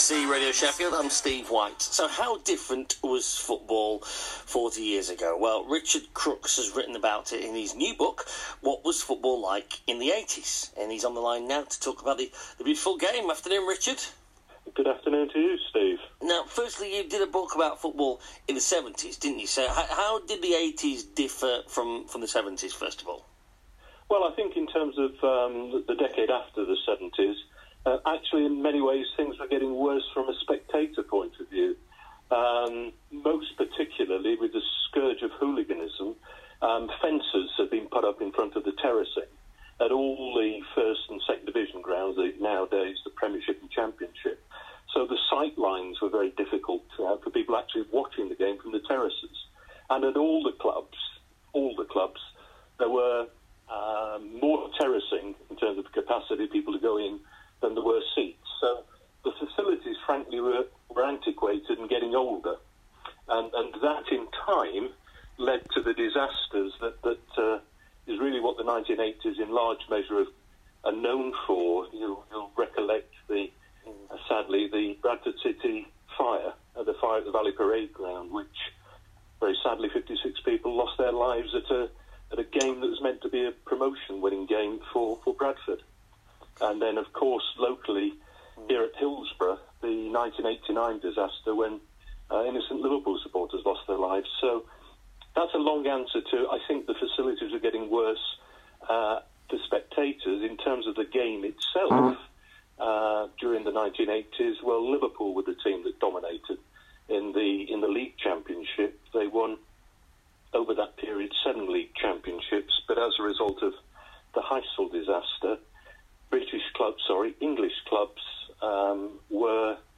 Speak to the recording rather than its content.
on BBC Radio Sheffield